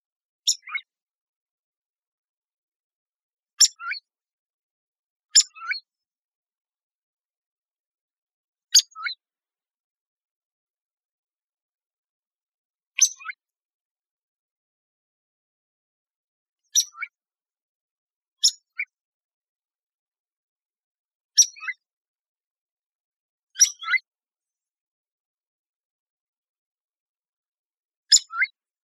groovebilledani.wav